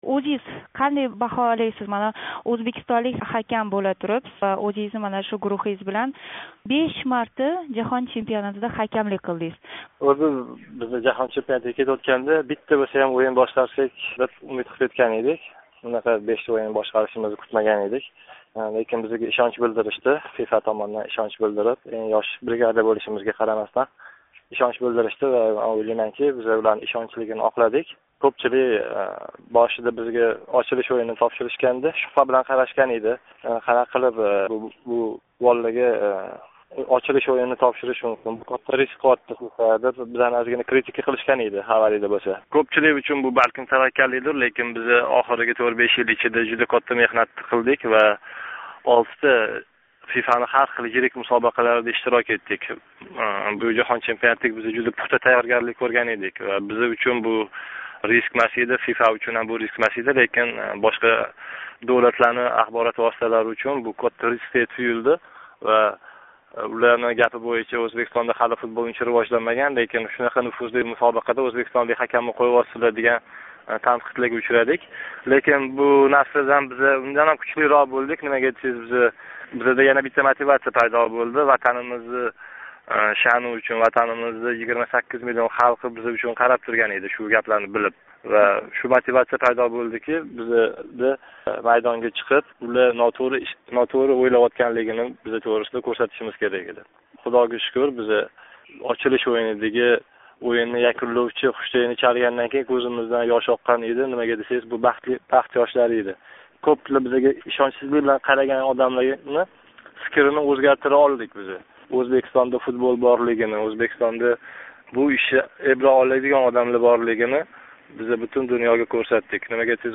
Равшан Эрматов билан суҳбат